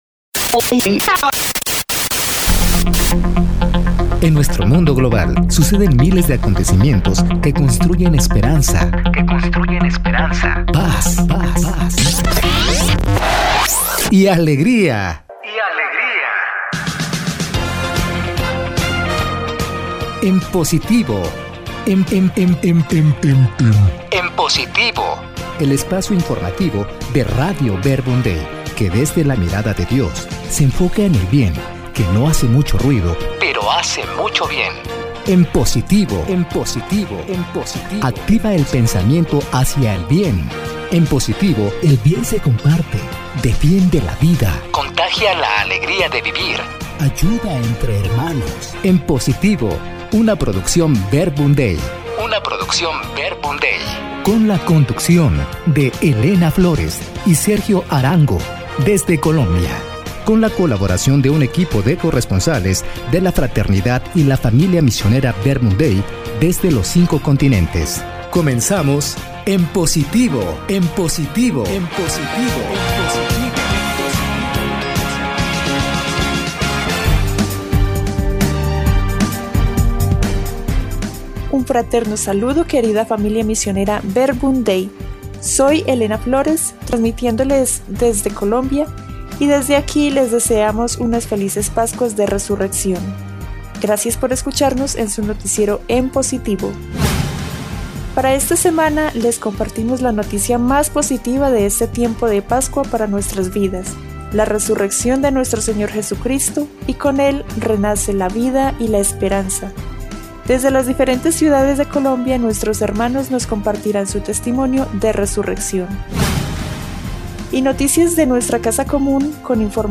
Los invitamos a escuchar el noticiero de este mes.